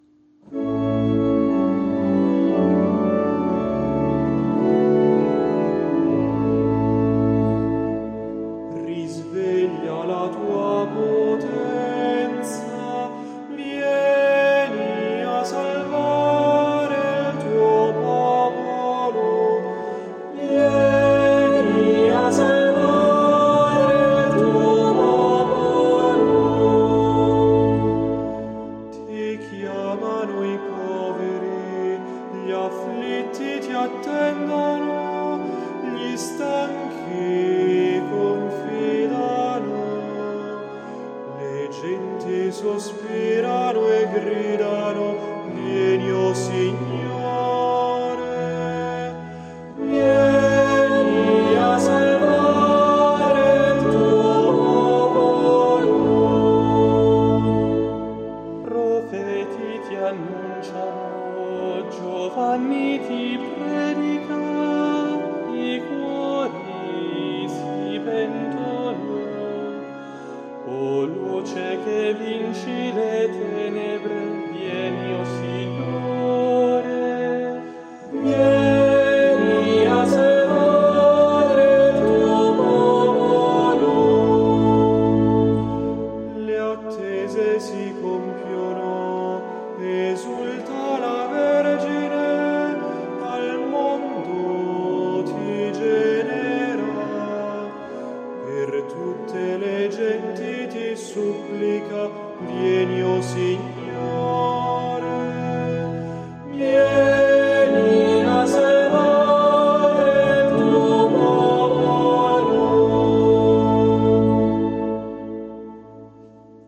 Richiede l’alternanza di un solista (che propone l’antifona) e l’assemblea (sostenuta dal coro) che ripete pedissequamente l’ultima parte della stessa.
Dopo le strofe si passa subito all’intervento dell’assemblea; dopo l’ultima strofa è possibile ripetere tutta l’antifona.
L’audio esemplifica questa forma musicale.